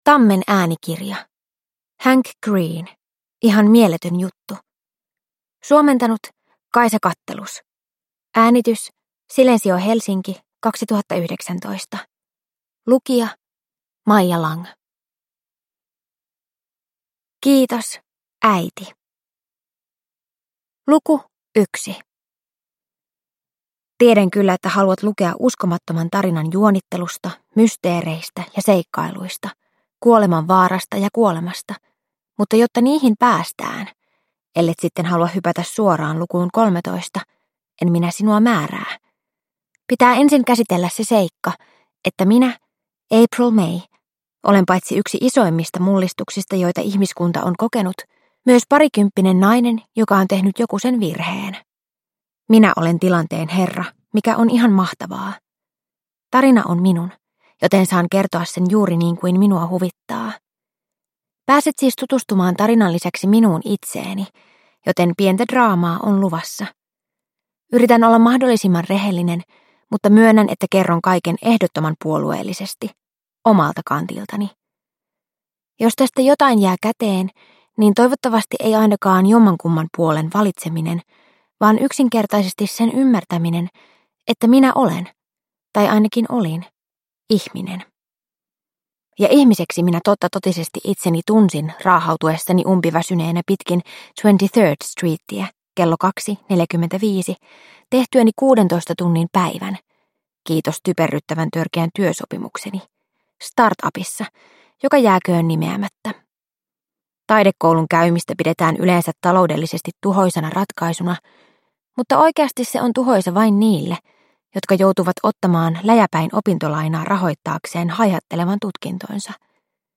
Ihan mieletön juttu – Ljudbok – Laddas ner